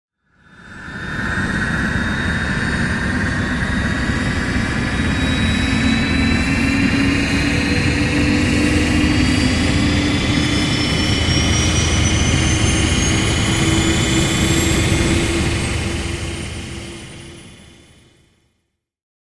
Turbine Fire Up
Turbine Fire Up.mp3